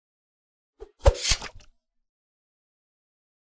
sword-001-00.ogg